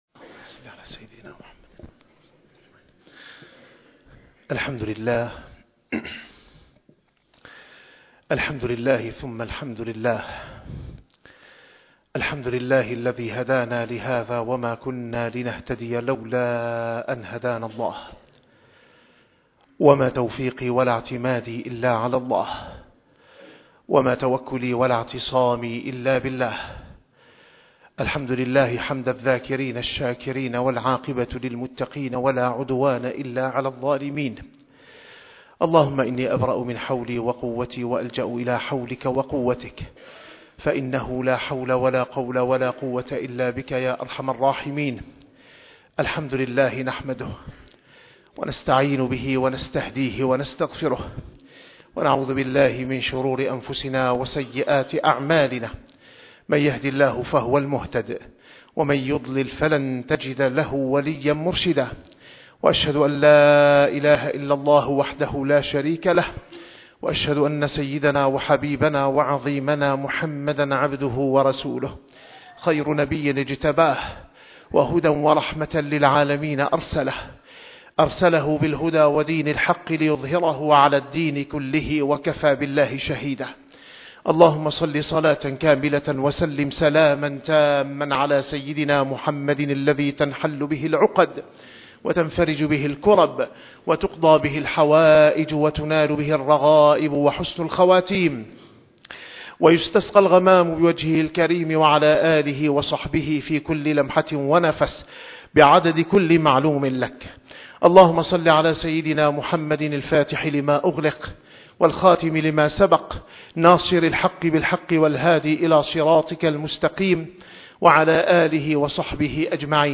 - الخطب - مفتاح الفرج